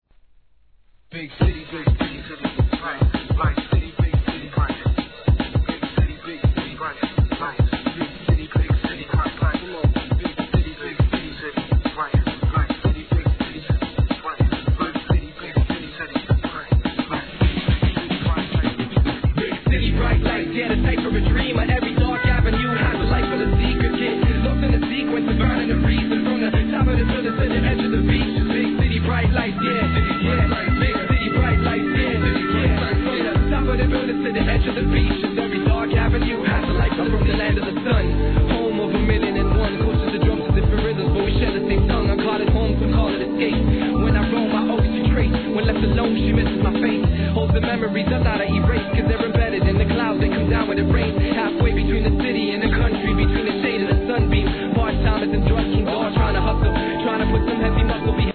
HIP HOP/R&B
心地よいサックスの旋律が絡むトラックに、畳み込むRAPを乗せたオリジナルな逸品!!